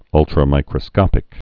(ŭltrə-mīkrə-skŏpĭk)